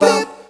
Hip Vcl Kord 2-A.wav